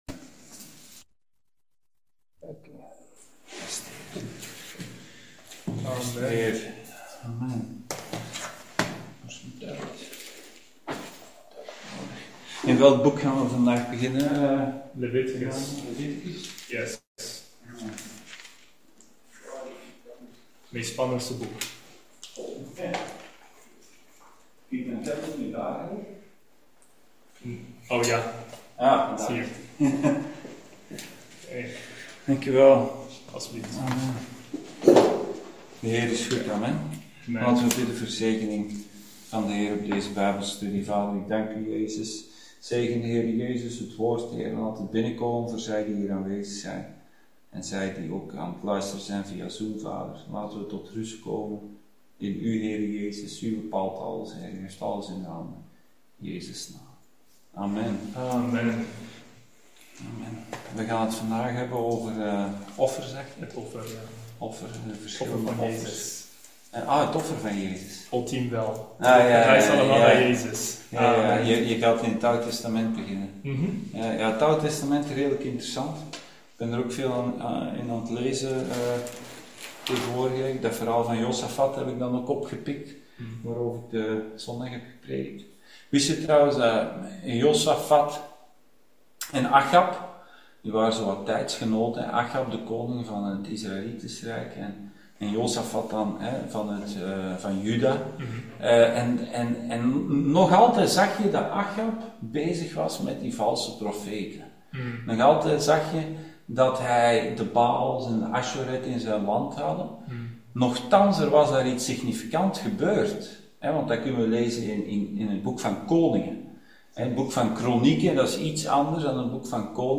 Tabernakel Dienstsoort: Bijbelstudie « Josafat’s Leger